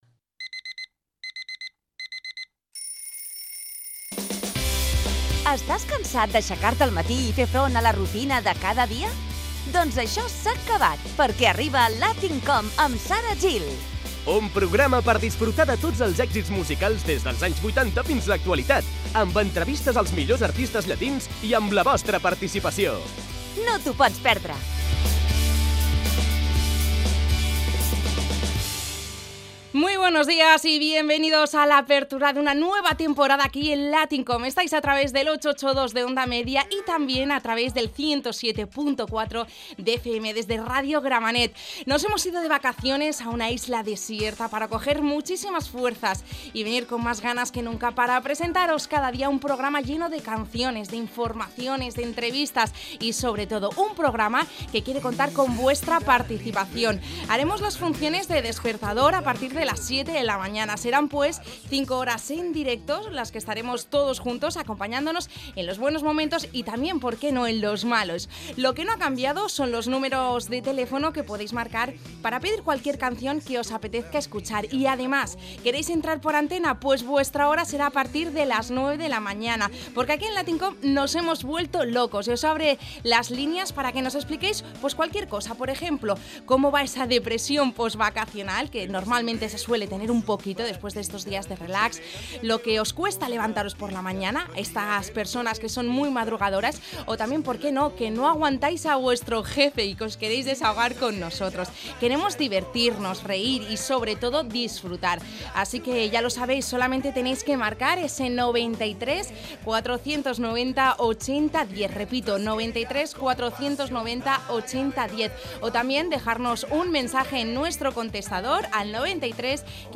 Careta i inici del programa
Entreteniment